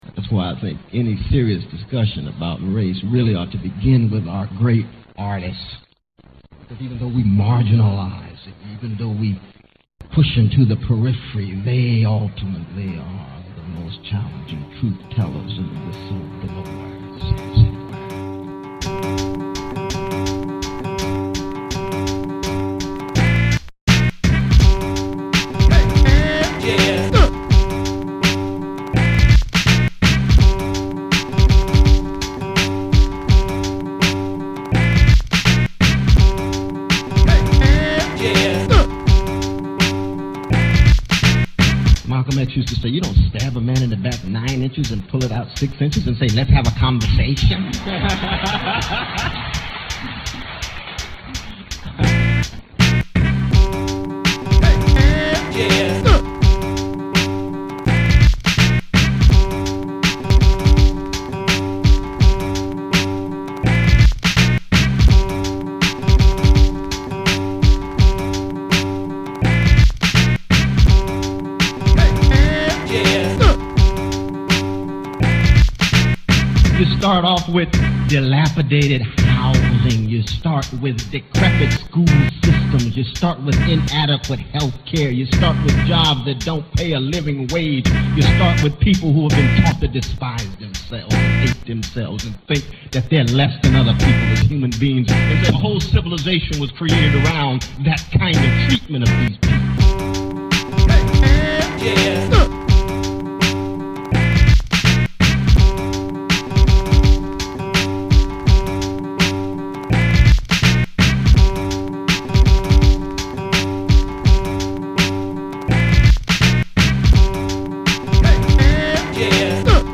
are assembled from syllables sung in the original songs